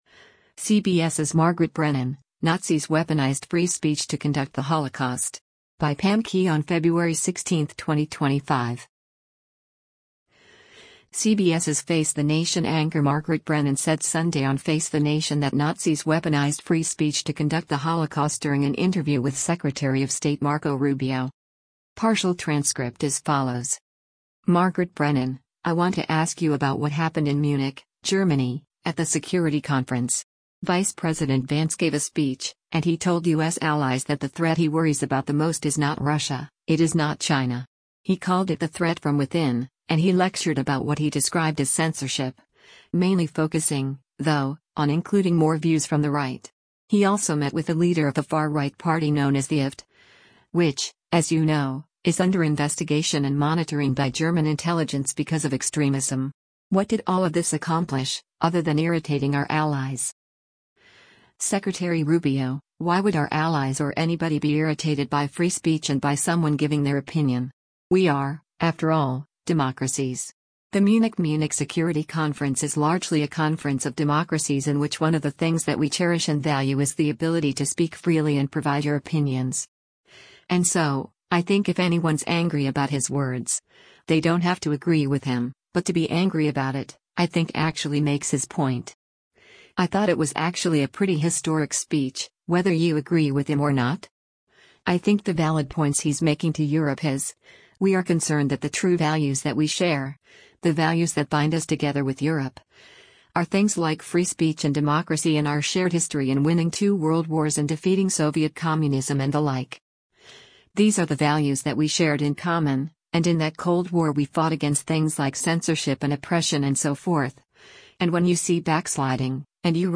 CBS’s “Face the Nation” anchor Margaret Brennan said Sunday on “Face the Nation” that Nazis “weaponized” free speech to conduct the Holocaust during an interview with Secretary of State Marco Rubio.